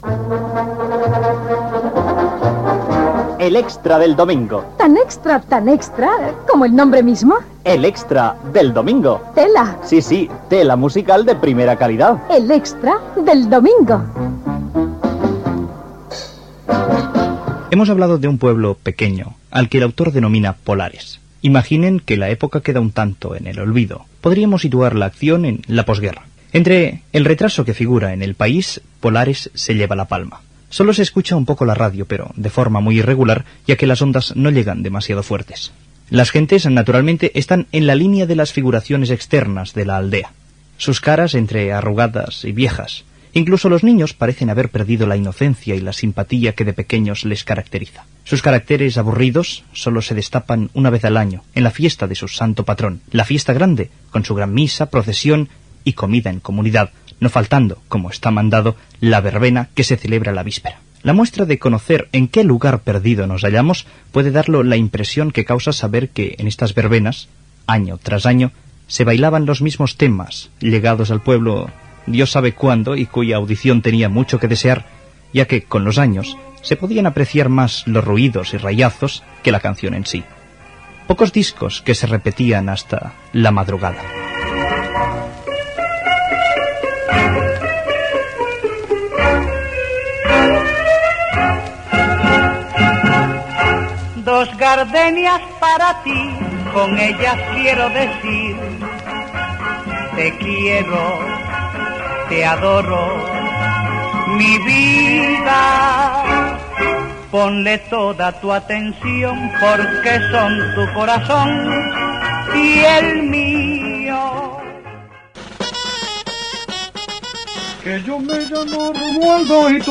Identificació del programa, final de l'adaptació lliure radiofònica de "Cementerio municipal" amb els noms de l'equip.